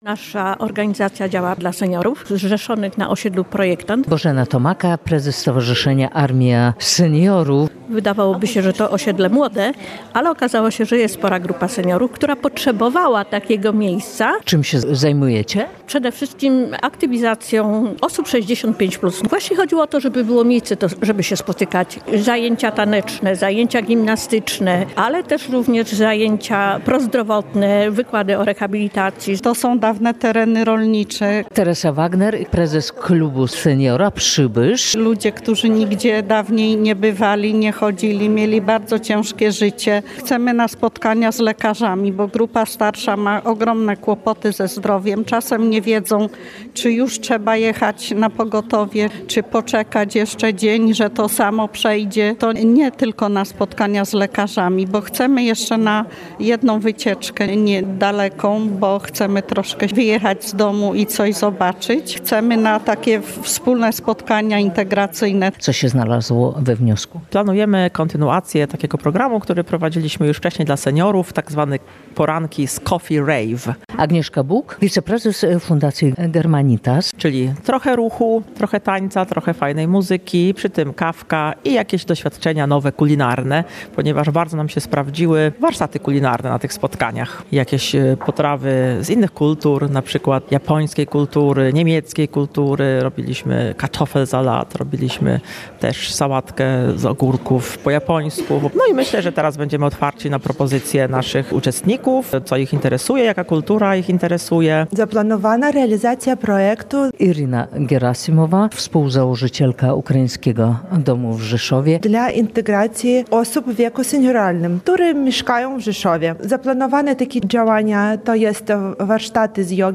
760 tys. zł przeznaczył Rzeszów na wsparcie organizacji pozarządowych • Relacje reporterskie • Polskie Radio Rzeszów